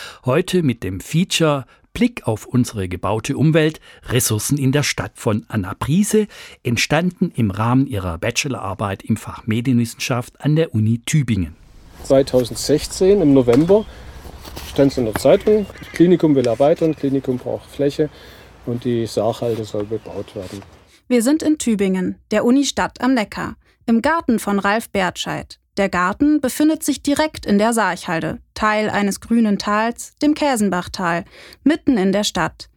Inhalt des Features: